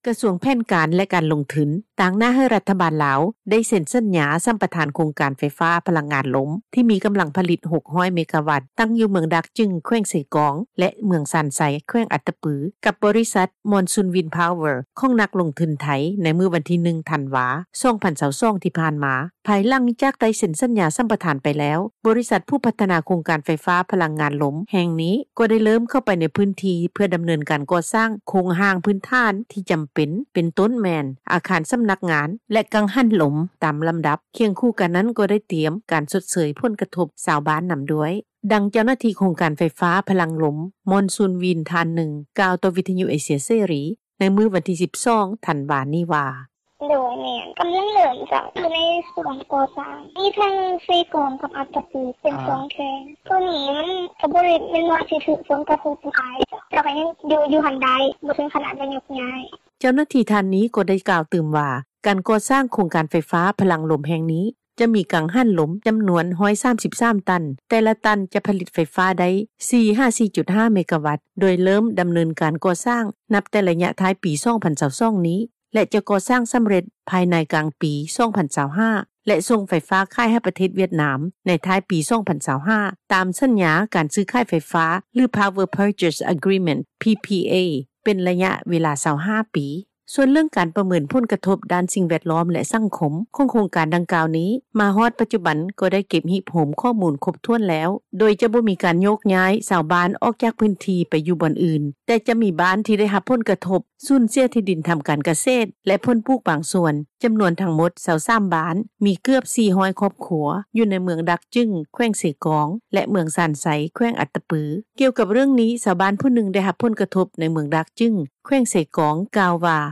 ດັ່ງ ຊາວບ້ານຜູ້ນີ້ ກ່າວຕໍ່ວິທຍຸເອເຊັຽເສຣີ ໃນມື້ດຽວກັນນີ້ວ່າ: